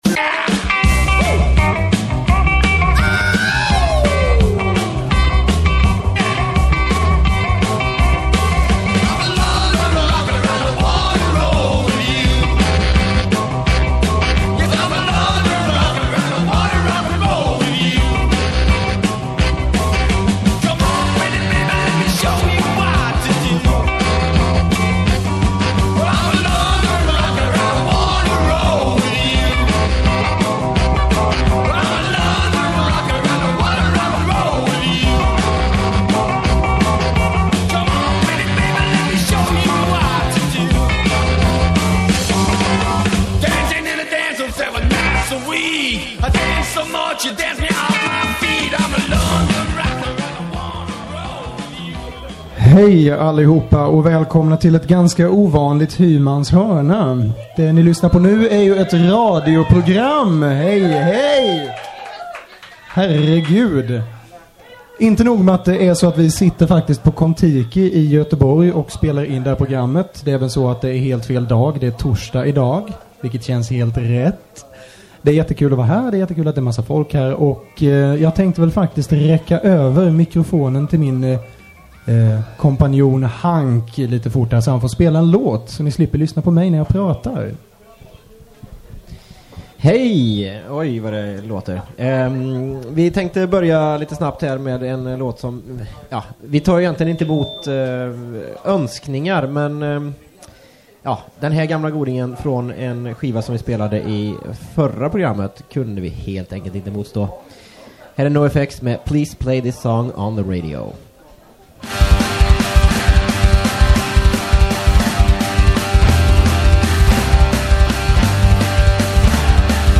Kvällens program �r ett specialprogram! Inspelat inf�r en livs levande publik p� Kontiki torsdagen den 7 februari.